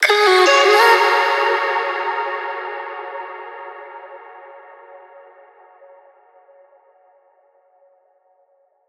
VR_vox_hit_couldntknow_D#min.wav